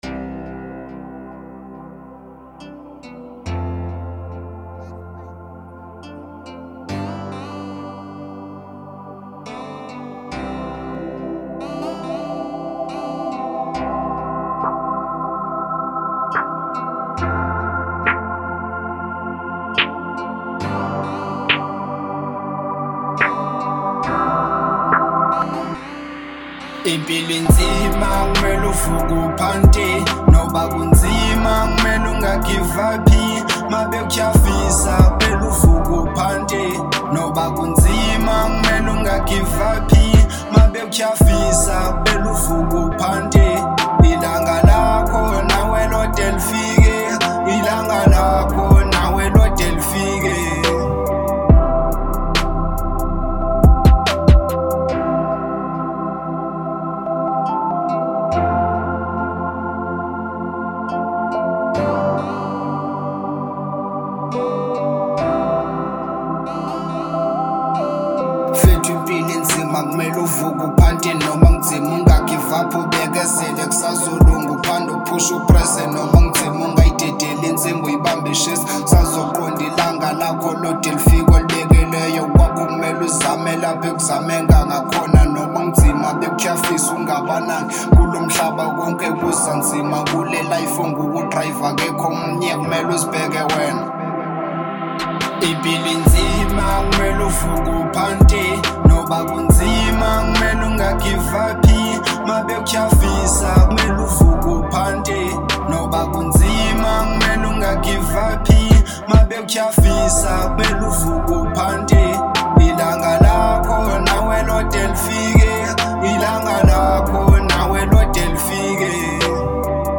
04:50 Genre : Hip Hop Size